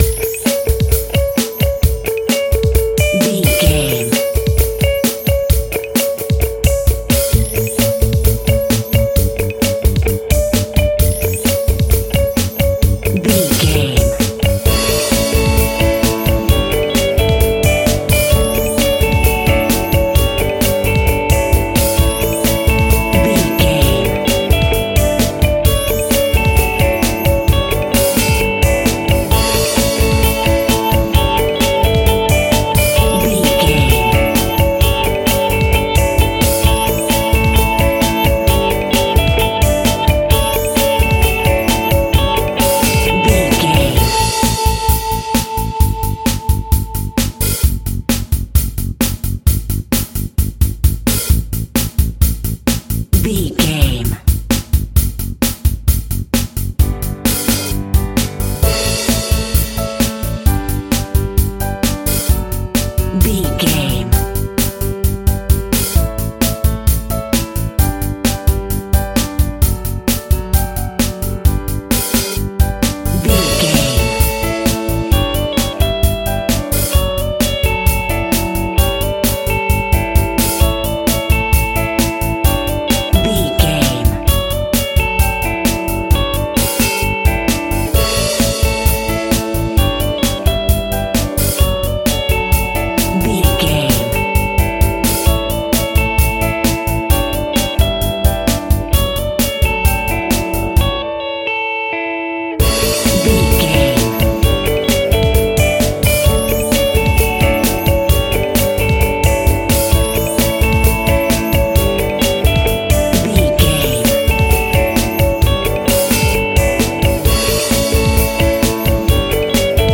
Ionian/Major
pop rock
indie pop
fun
energetic
uplifting
cheesy
drums
bass guitar
electric guitar
synthesizers